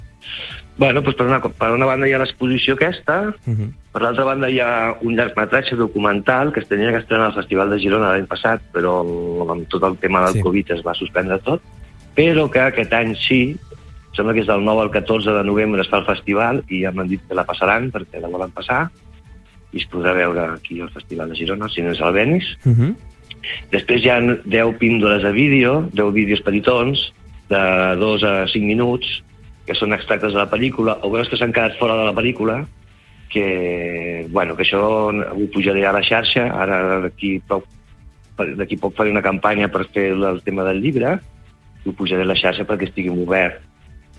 entrevista-2.mp3